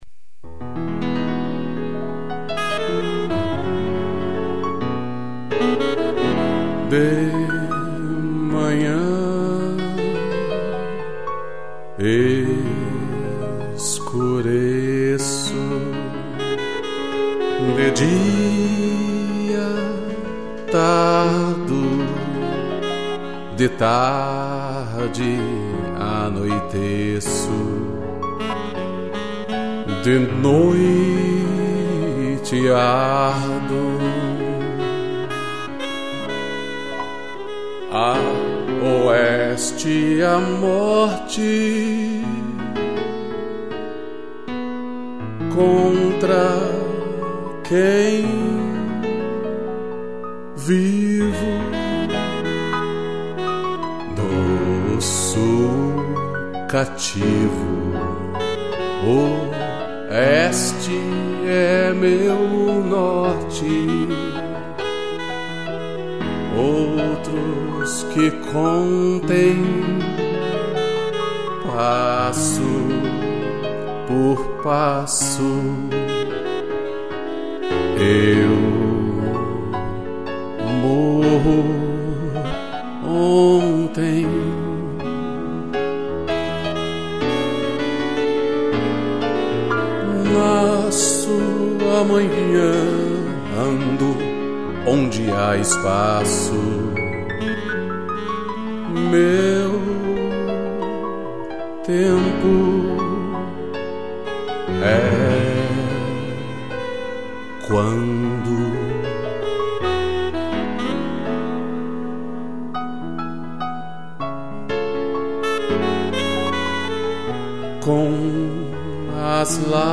Voz
2 pianos e sax